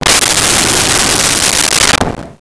Wind Howl 01
Wind Howl 01.wav